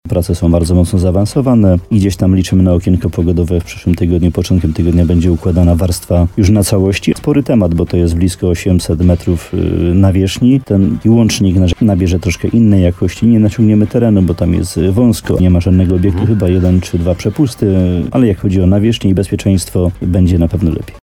Jak powiedział w programie Słowo za Słowo w radiu RDN Nowy Sącz wójt Łącka Jan Dziedzina, będzie to główny drogowy łącznik dwóch gmin i powiatów do czasu, dopóki gmina Łukowica nie ukończy mostu w Jadamwoli.